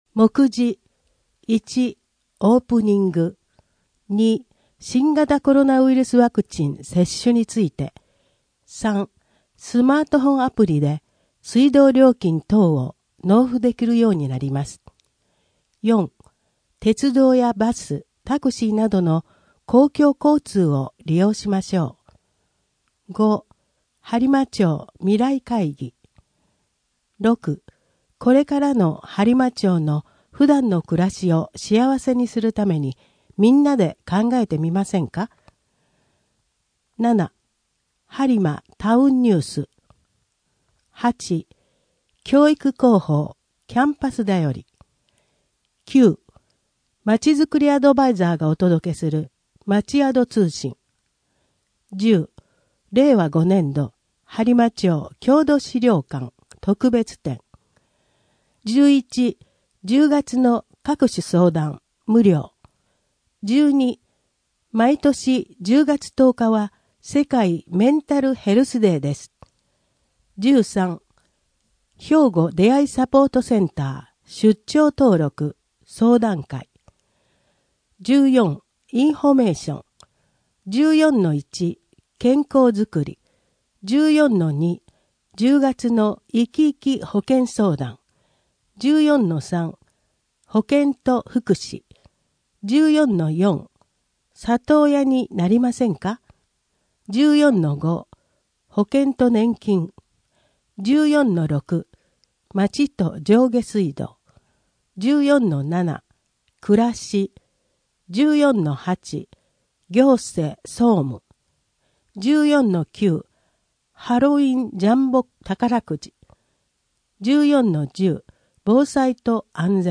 声の「広報はりま」10月号
声の「広報はりま」はボランティアグループ「のぎく」のご協力により作成されています。